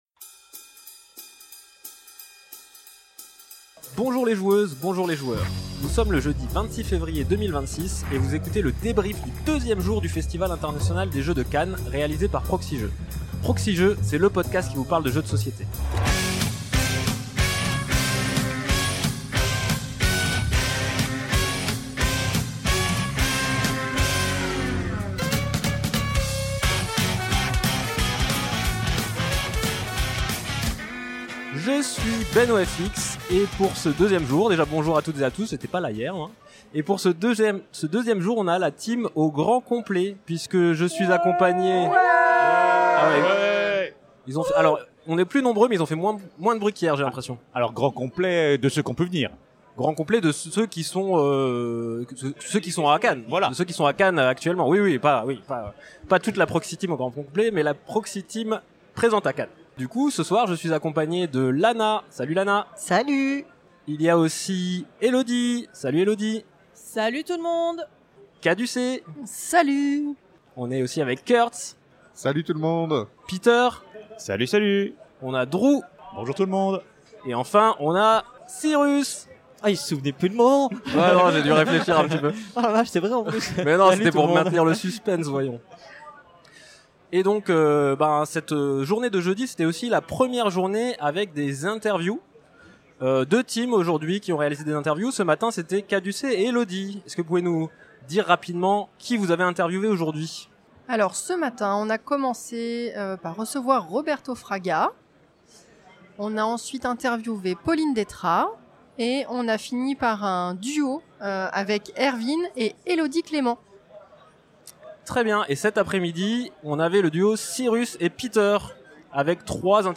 FIJ 2026, jeudi, écoutez le ressenti à chaud de la délégation de Proxi-Jeux présente au Festival International des Jeux de Cannes 2026.
Jeudi, deuxième jour du FIJ 2026 ! Des interviews et des jeux !